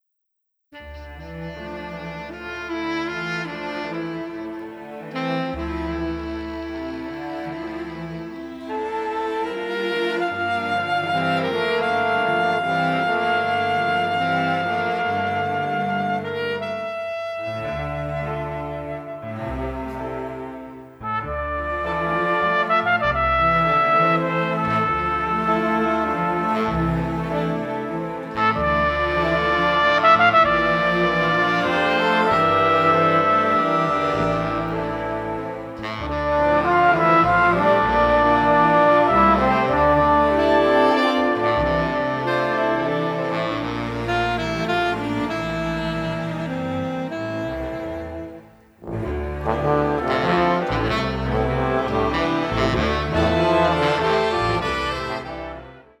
melancholic jazz music
saxophonists